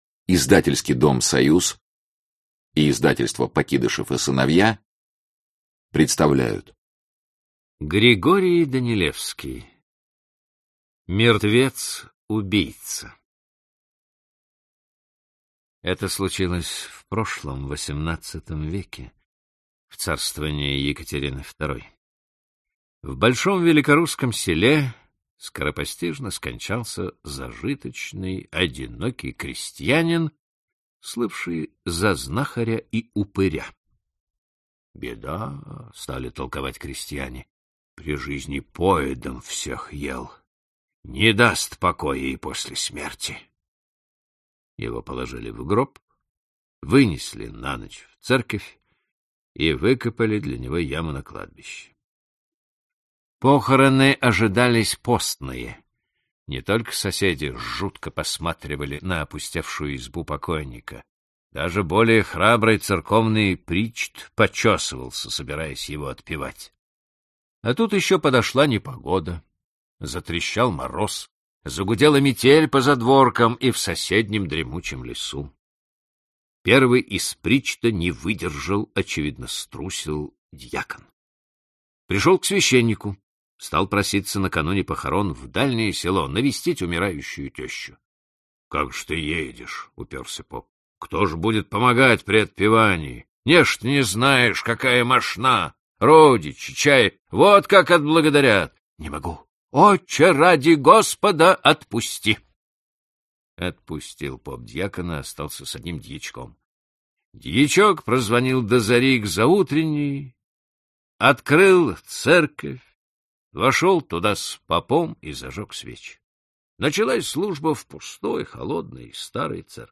Аудиокнига Классика русского детективного рассказа № 2 | Библиотека аудиокниг